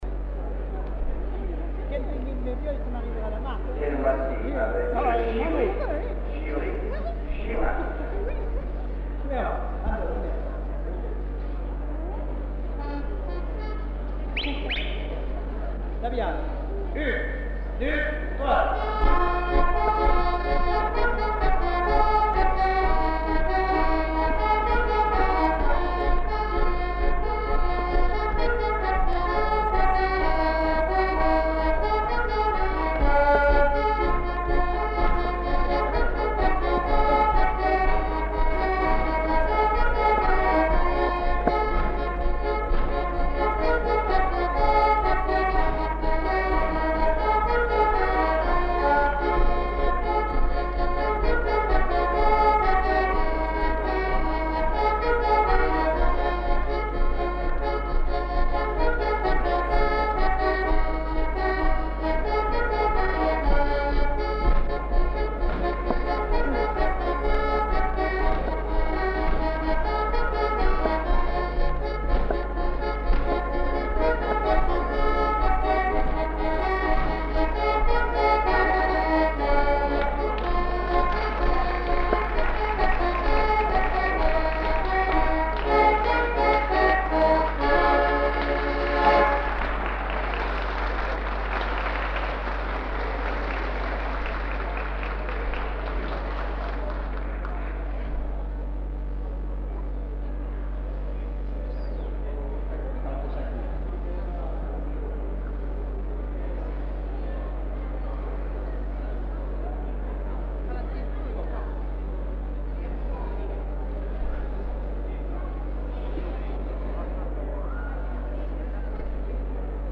Aire culturelle : Grandes-Landes
Lieu : Samatan
Genre : morceau instrumental
Instrument de musique : accordéon diatonique